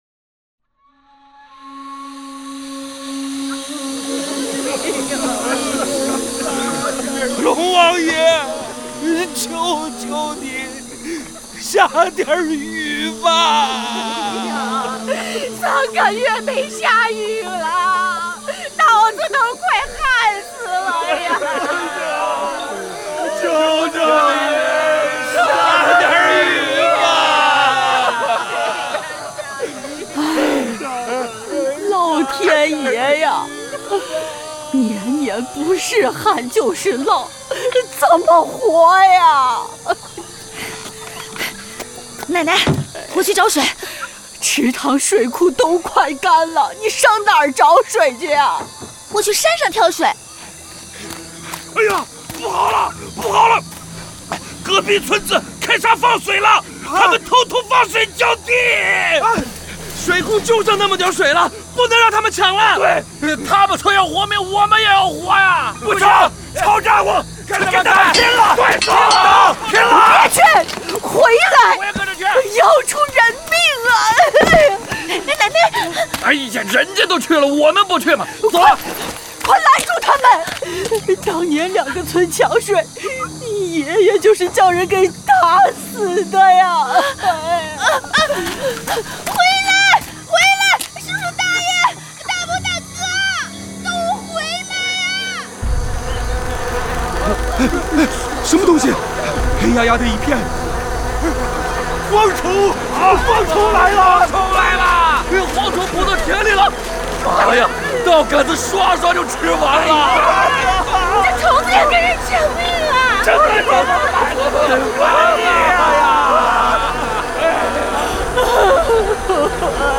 广播类型：连续剧